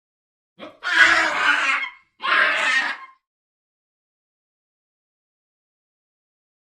На этой странице собраны разнообразные звуки бабуина — от громких криков до ворчания и общения в стае.
Звук бабуина с криком в клетке